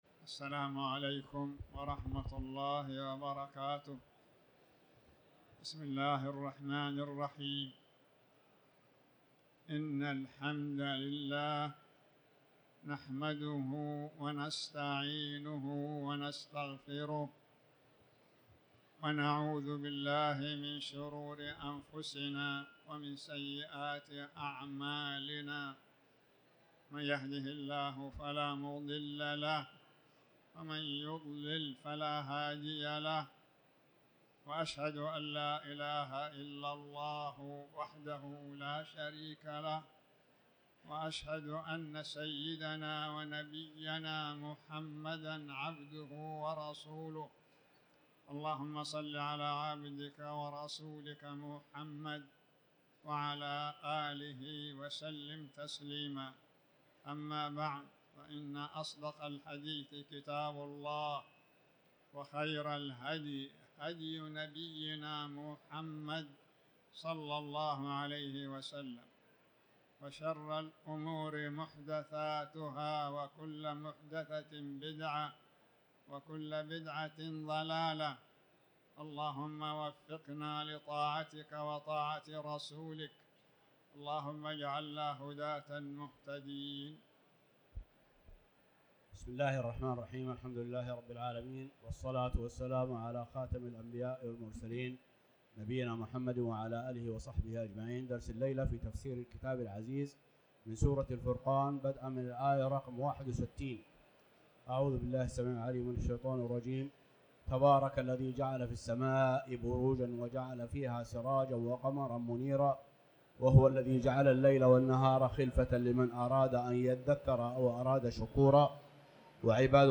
تاريخ النشر ٢٦ شعبان ١٤٤٠ هـ المكان: المسجد الحرام الشيخ